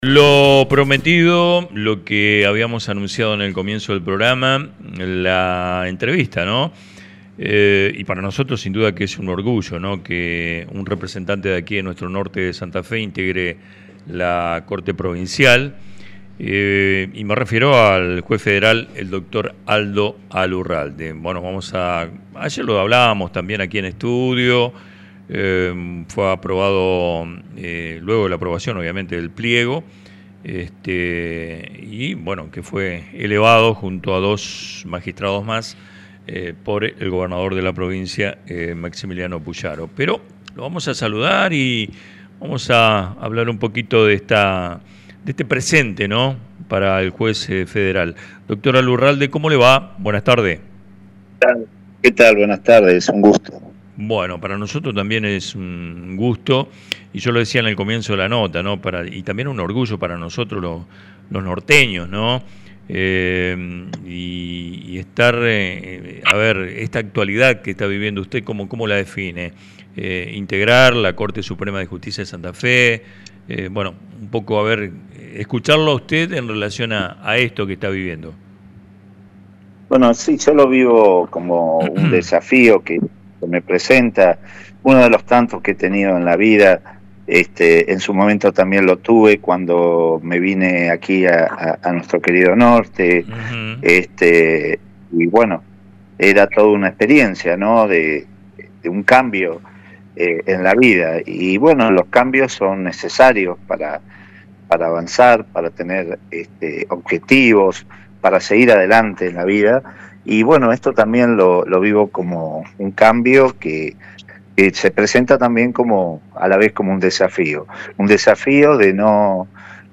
En diálogo con el programa Vivos y Sueltos, que se emite por Radio Ideal 94.9, el juez federal Aldo Mario Alurralde habló tras su designación como nuevo ministro de la Corte Suprema de Justicia de Santa Fe, luego de que la Legislatura aprobara su pliego a propuesta del gobernador Maximiliano Pullaro.